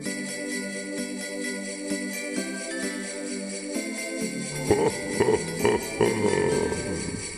Звуки Санта-Клауса
Хо Хо Хо Санта Клауса с веселой мелодией